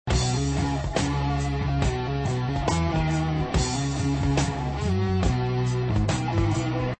heavy_metal.mp3